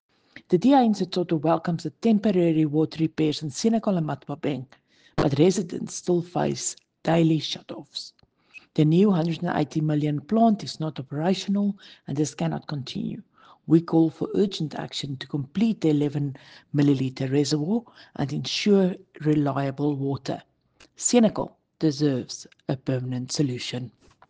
Afrikaans soundbites by Cllr Riëtte Dell and